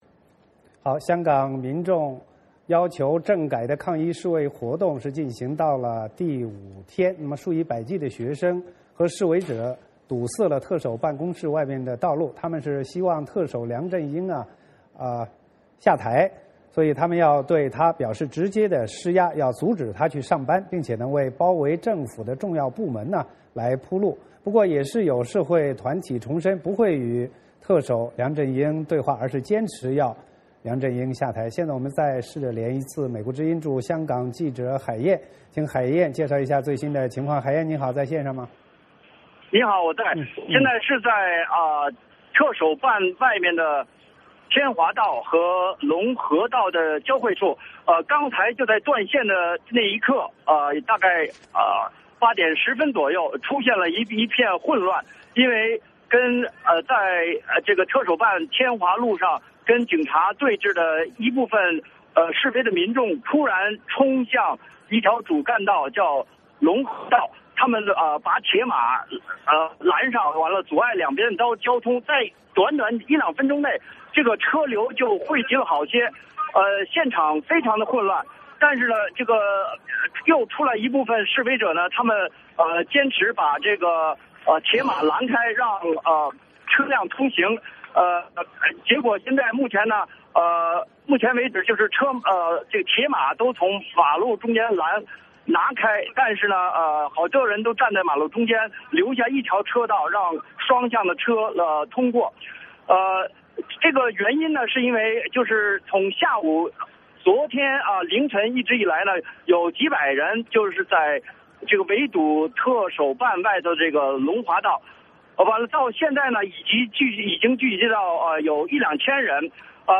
VOA连线：香港“占中”进入第五天